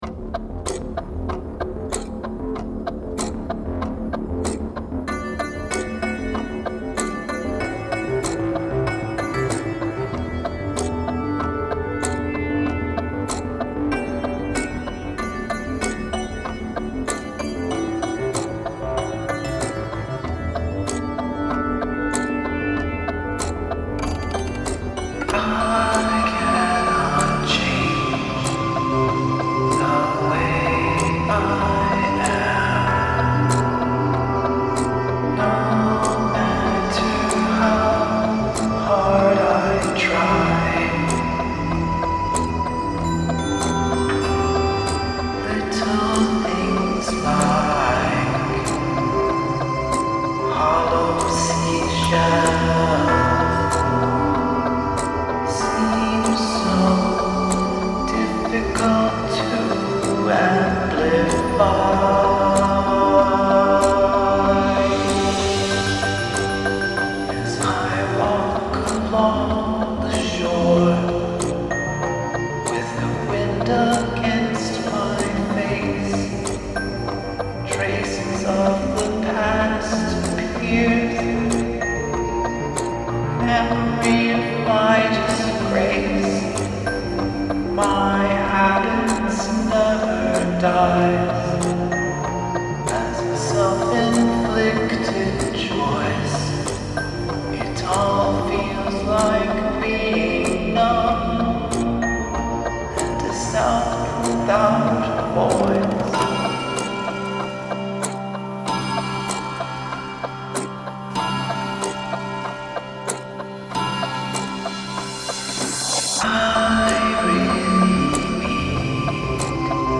A brief explanation of how we approached recording a cover